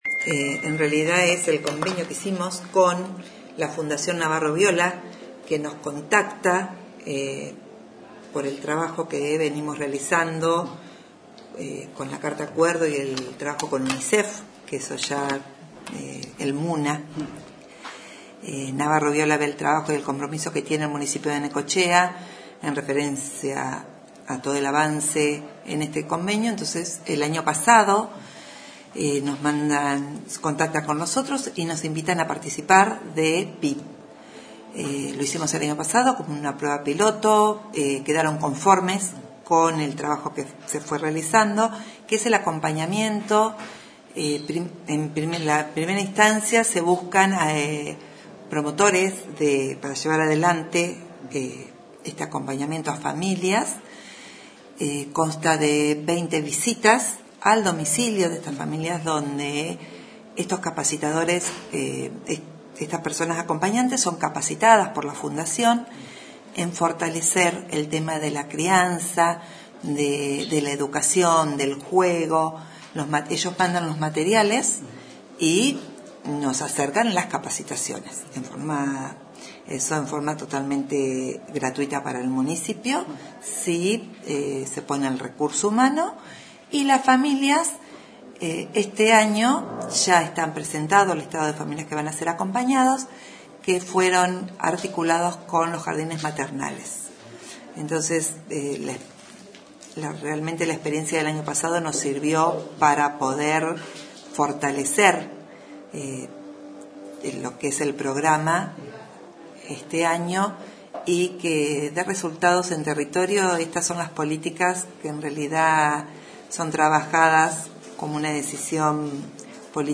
La secretaria de Desarrollo Humano, Sandra Antenucci, explicó cómo se implementará el programa Primera Infancia Primero este año en la ciudad.